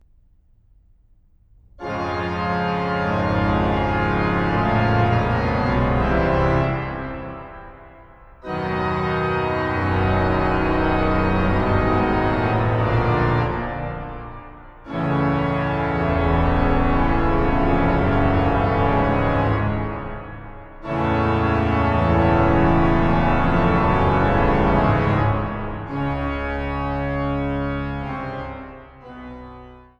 Rieger Orgel der St. Katharinenkirche Frankfurt am Main
Orgel